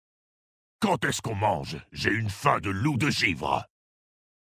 VF_OrcMaghar_Male_Blague_Faimdeloup.mp3